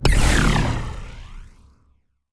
Alien weapon/blast sounds
22khz mono already.
plasma1_106.wav